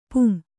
♪ pum/pun